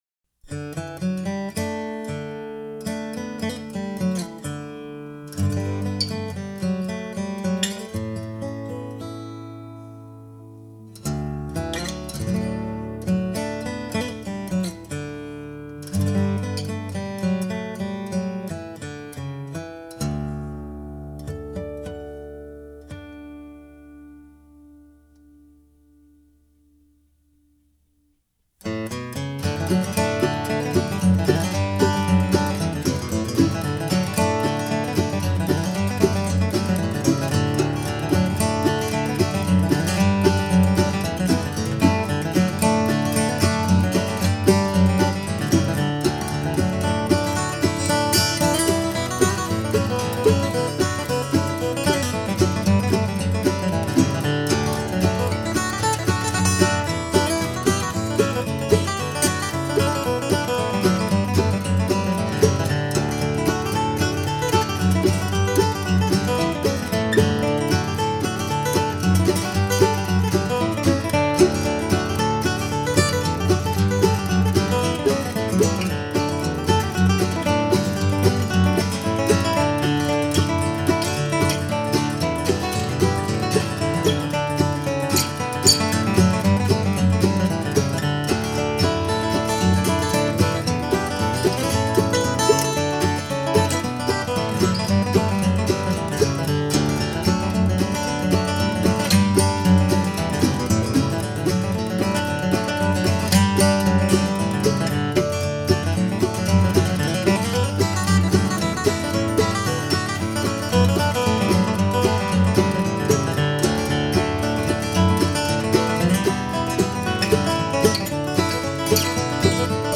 Whiskey Before Breakfast | Guitar